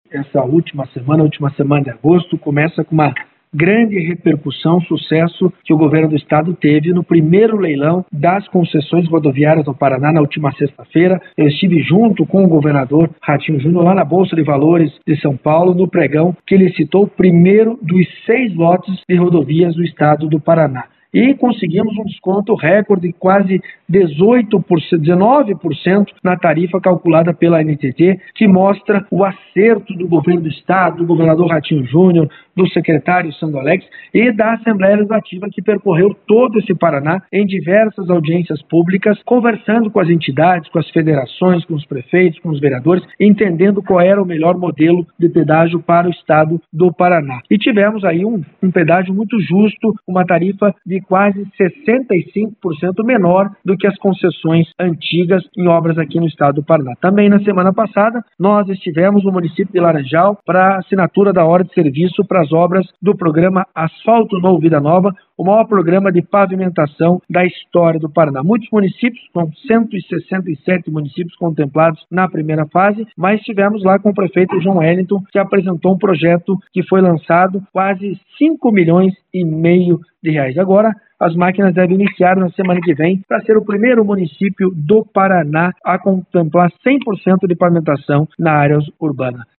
O deputado Estadual do Paraná, Alexandre Curi, fez um resumo dos últimos acontecimentos no Estado com exclusividade à Rádio Colmeia nessa quarta-feira, 30. Entre os assuntos estão a concessão do pedágio e o programa de pavimentação.
Você pode acompanhar a fala do deputado nos áudios abaixo.